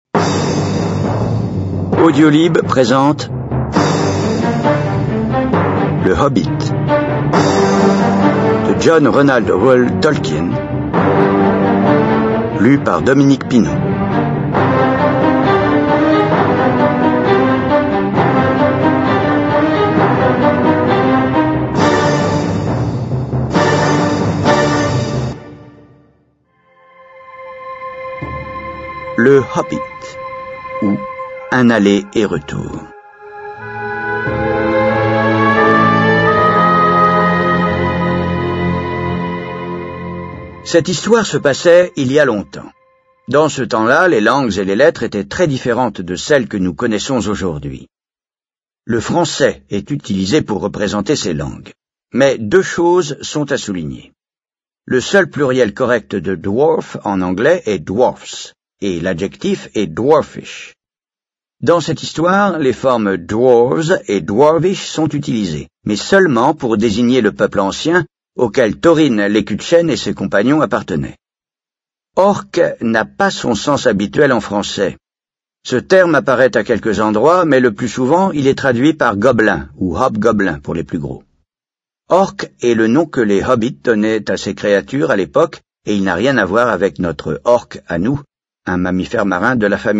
Le Hobbit - Livre audio
Lecture d'ailleurs de la nouvelle traduction si je ne m'abuse.